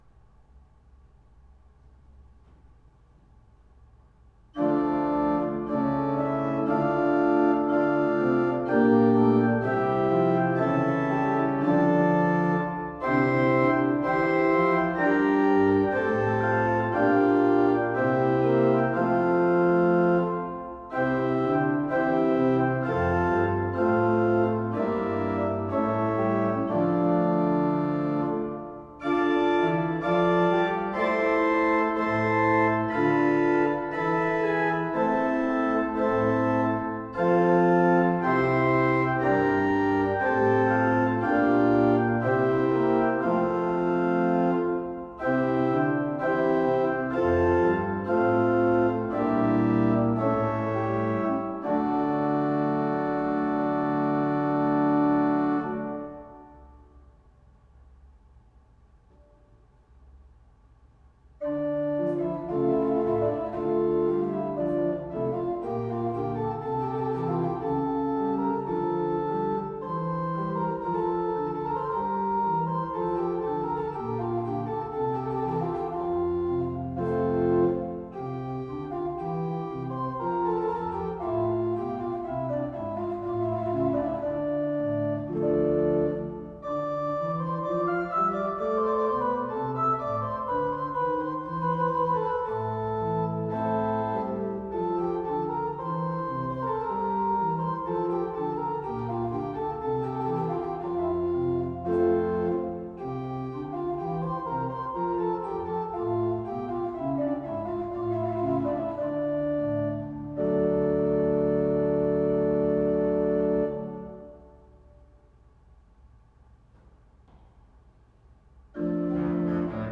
Mp3 (Kerkorgel)
Partita in de stijl van G.Bohm